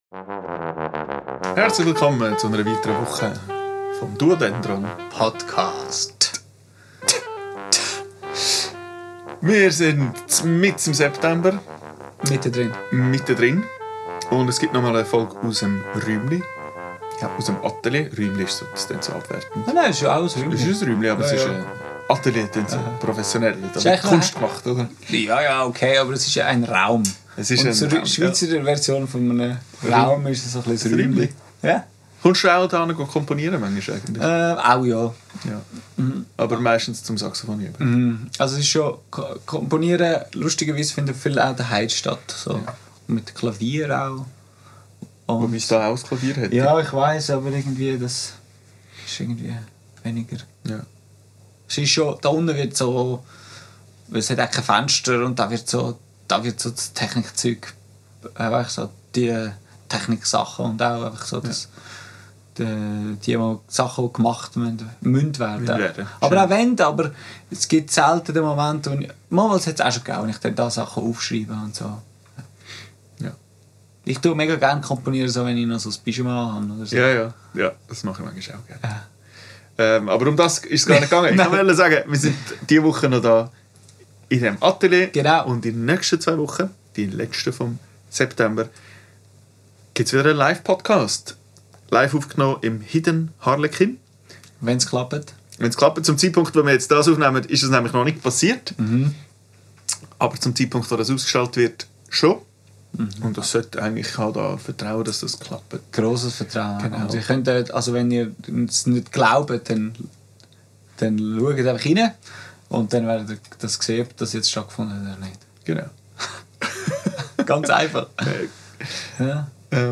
Aufgenommen am 28.08.2025 im Atelier